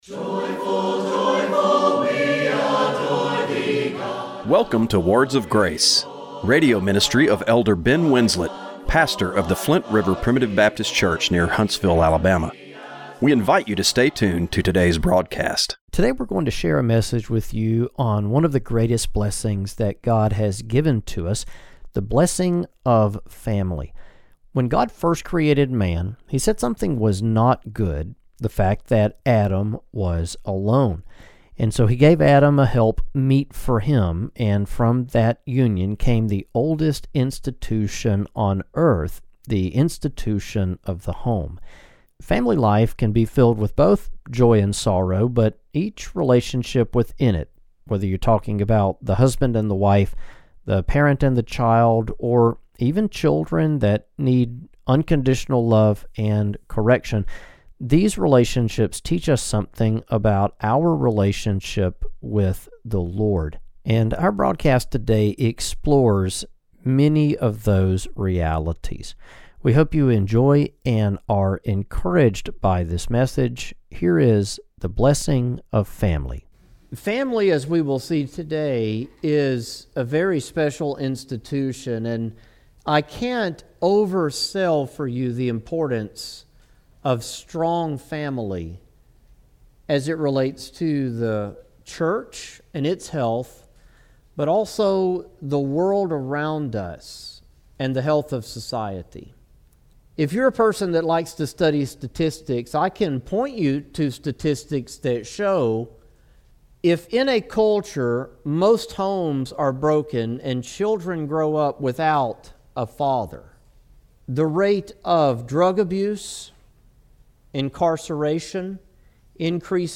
Radio broadcast for August 31, 2025.